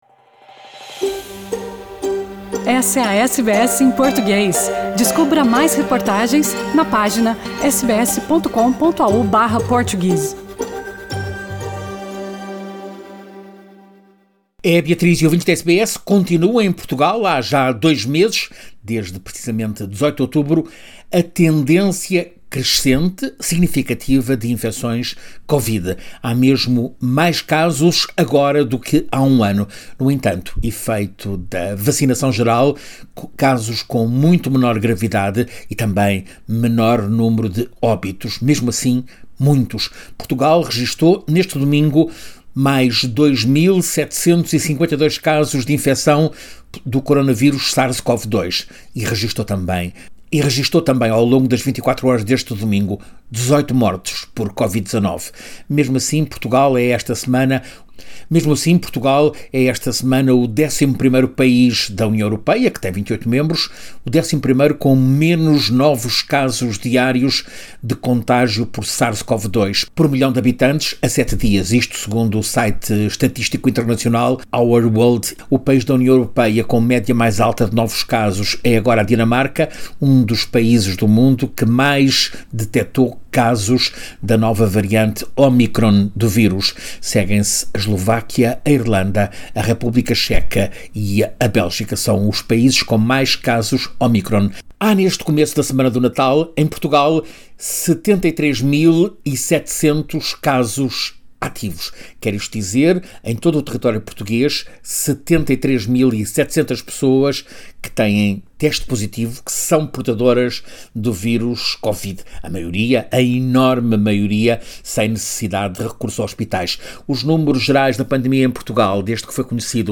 SBS em Português